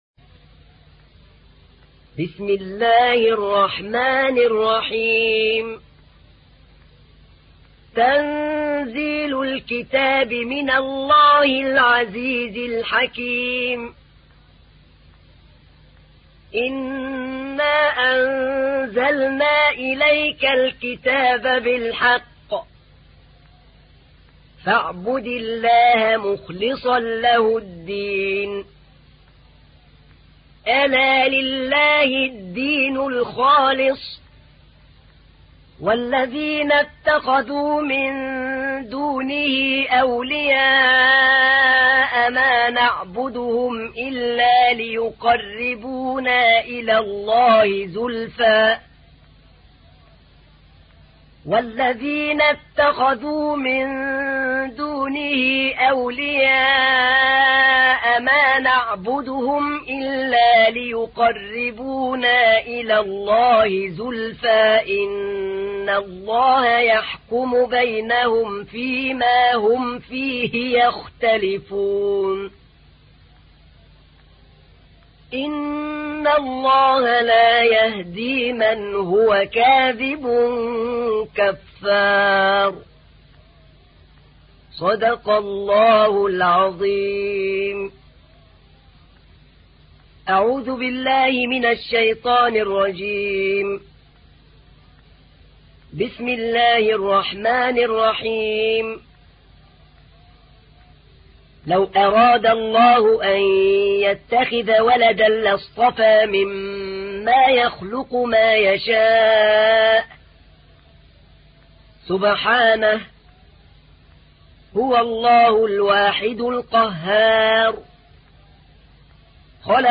تحميل : 39. سورة الزمر / القارئ أحمد نعينع / القرآن الكريم / موقع يا حسين